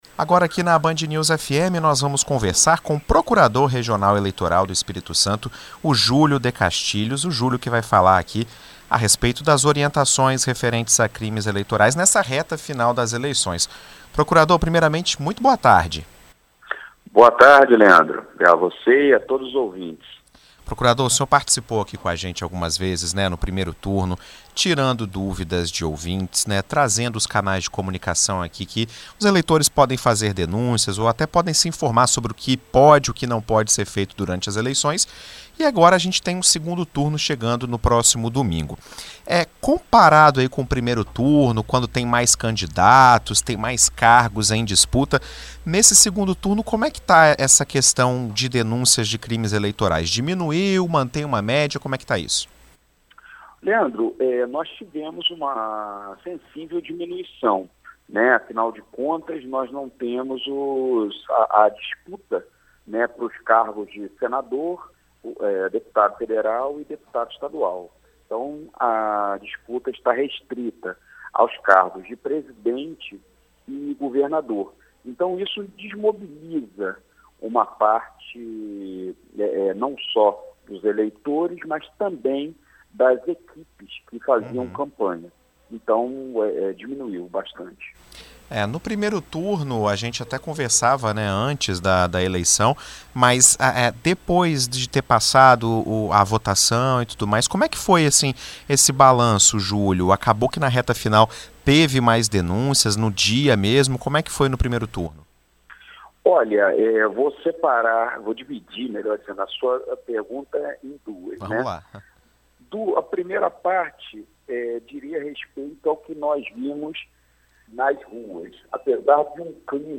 Em entrevista à BandNews FM ES nesta terça-feira (25), o procurador regional eleitoral, Julio de Castilhos fala sobre o que pode e o que não pode ser feito nessa reta final das eleições. Ele analisa que no segundo turno, com menos cargos em disputa, o número de crimes eleitorais e propagandas irregulares são reduzidos.